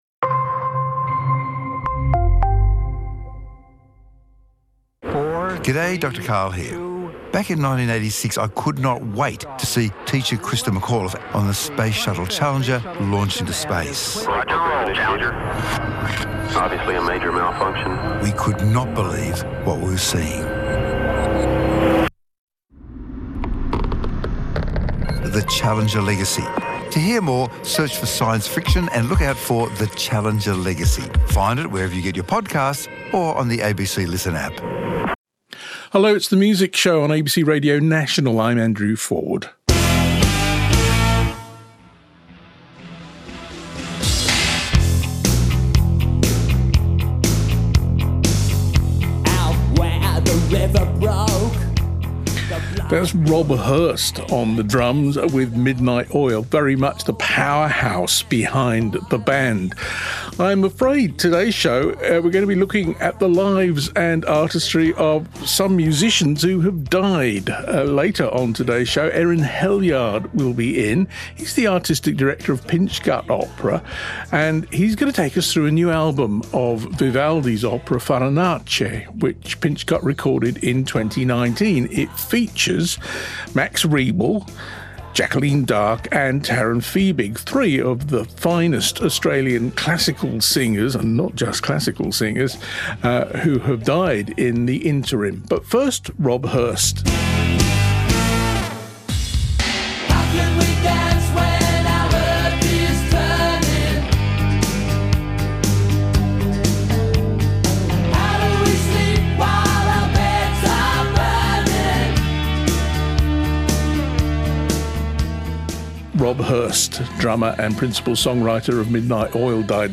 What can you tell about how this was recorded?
She returns to The Music Show studio to perform live with an eclectic trio